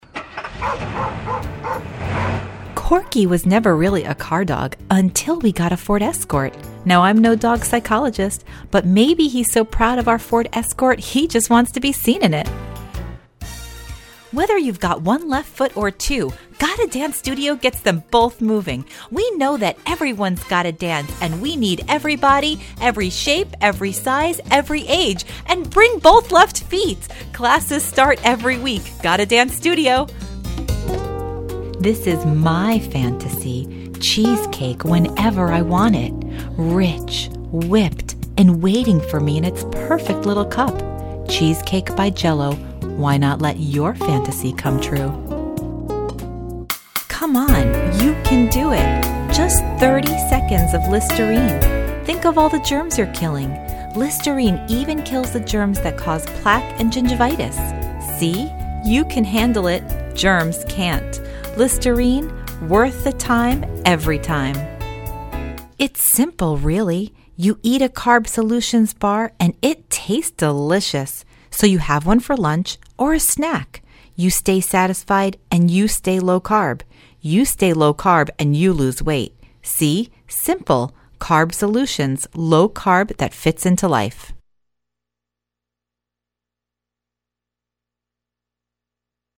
Voice Over Artist